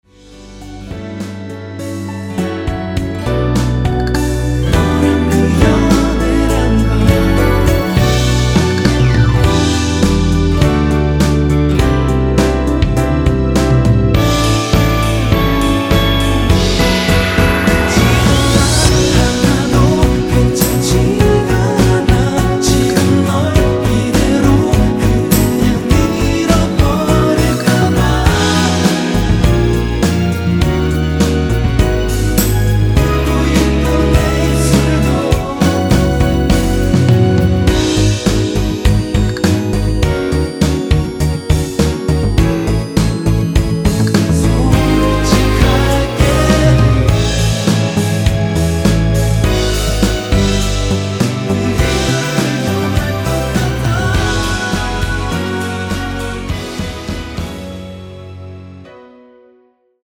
원키 코러스 포함된 MR입니다.
D
앞부분30초, 뒷부분30초씩 편집해서 올려 드리고 있습니다.
중간에 음이 끈어지고 다시 나오는 이유는